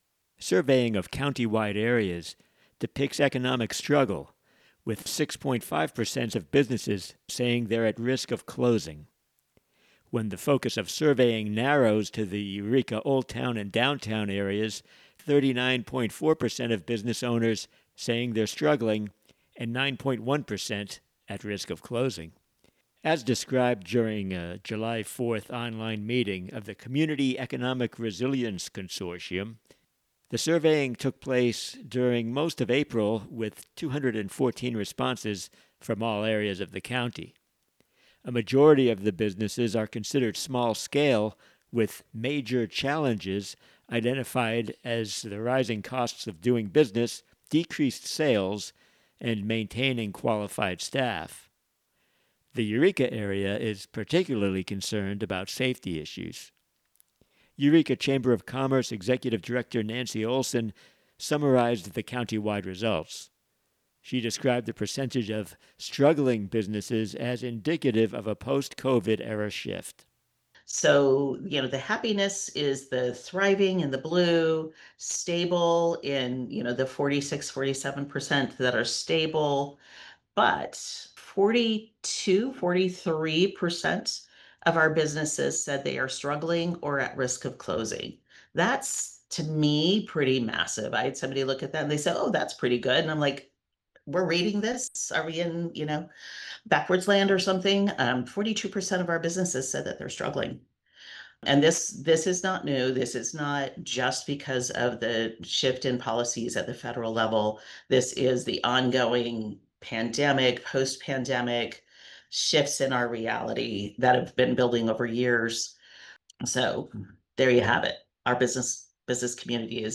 KMUDs Local News report for 11/18/24